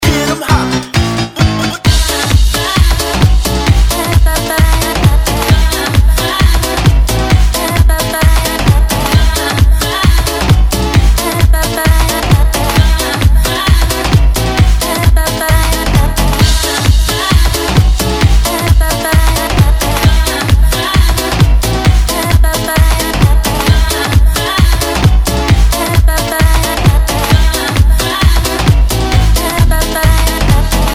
• Качество: 192, Stereo
заводные
Знакомая мелодия в новом звучании.